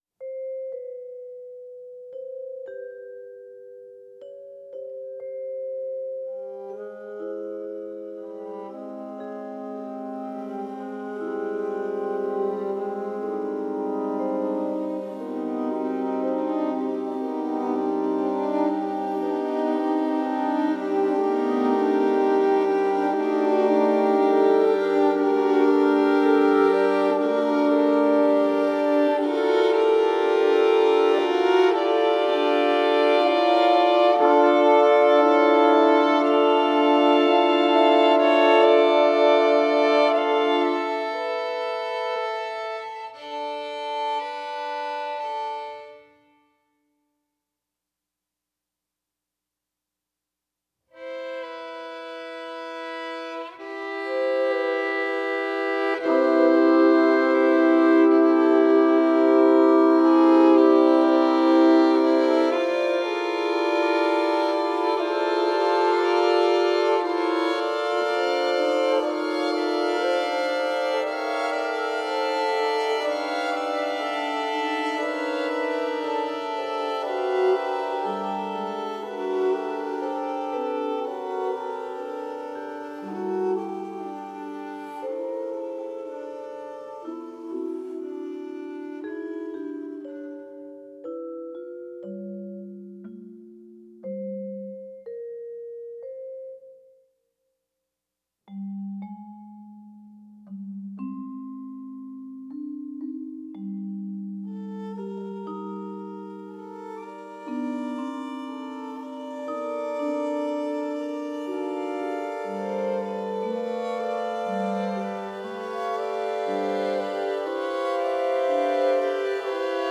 violin
viola
celesta
horn
vibraphone
flute
clarinet